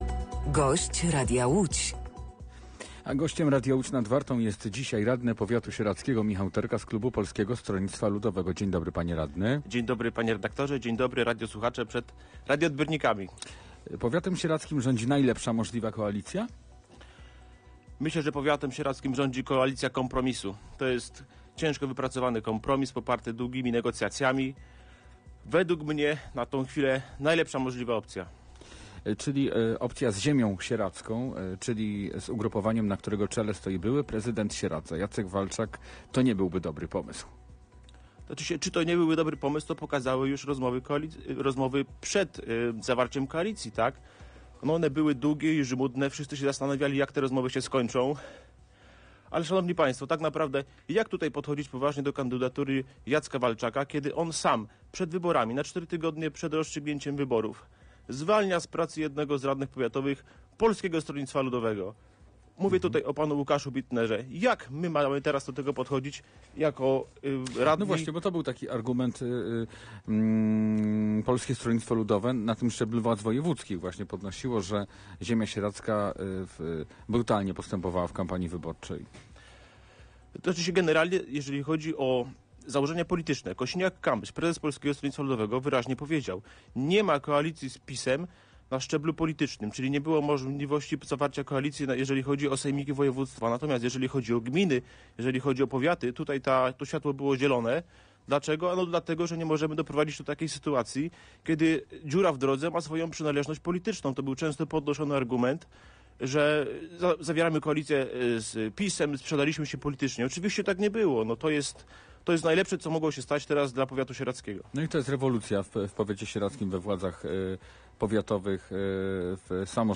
Gościem Radia Łódź Nad Wartą był radny Rady Powiatu Sieradzkiego z PSL Michał Terka.